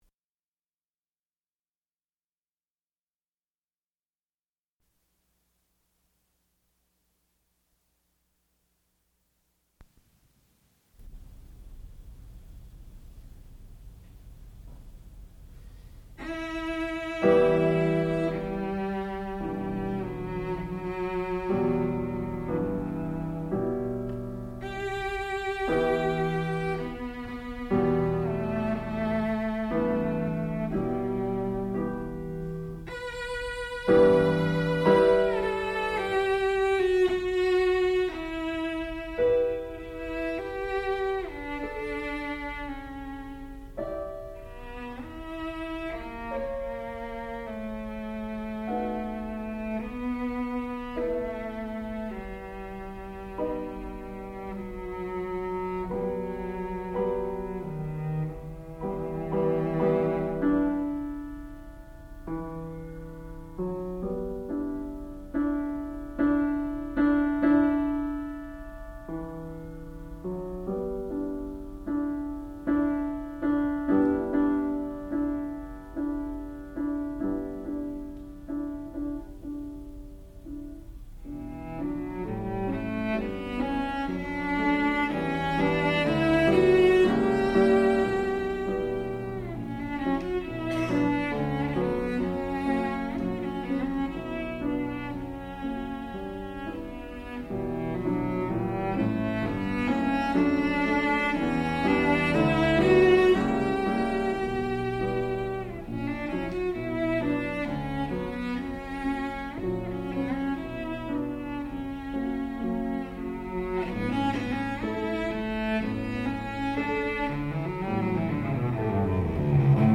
sound recording-musical
classical music
violoncello